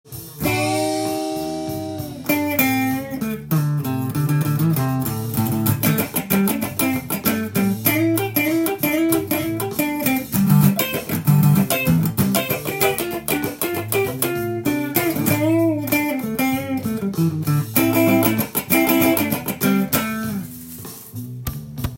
ブルースのソロでカッティングを弾くこともできます。
カッティング【Aブルースソロに入れる方法】tab譜付き
譜面通りに弾いてみました
それらしく弾くことが出来るのでチャカチャカとミュートを挟みながら